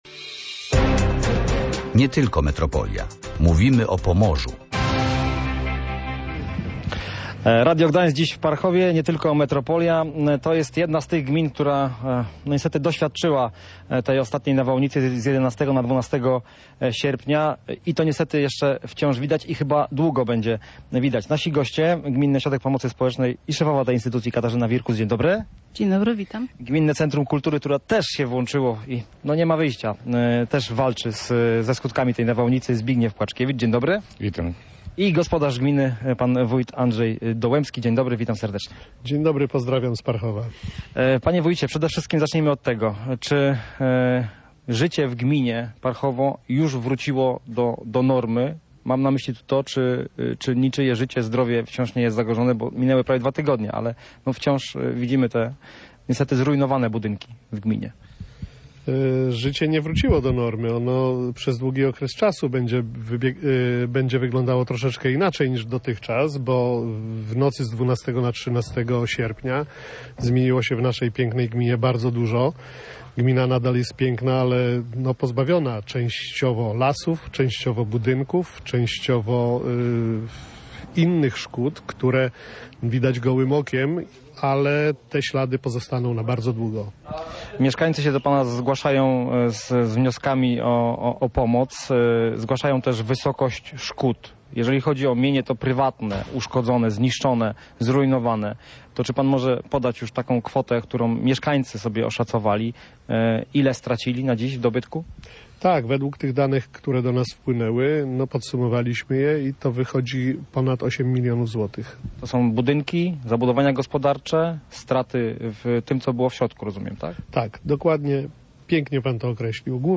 w programie Nie tylko Metropolia nadawanym z Parchowa